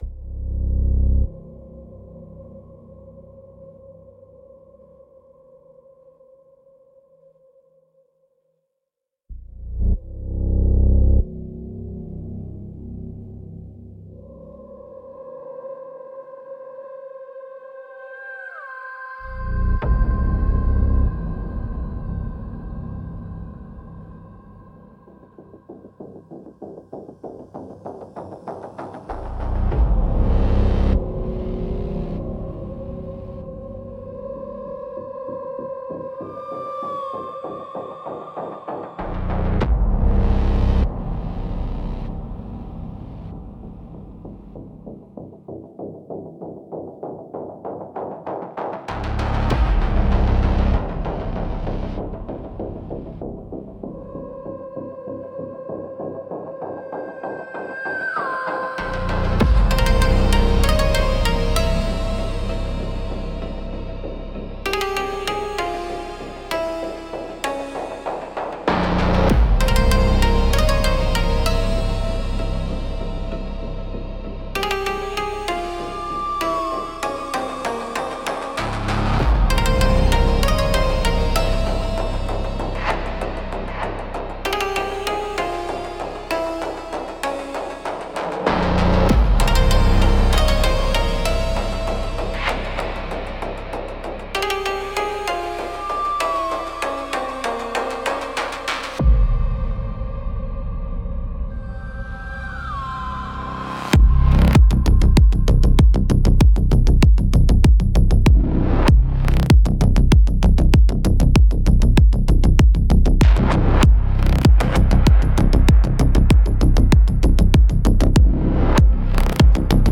Instrumentals - Cathedral of Scrap